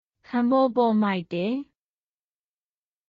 カン　ポーポー　マイテー
当記事で使用された音声（日本語およびミャンマー語）はGoogle翻訳　および　Microsoft Translatorから引用しております。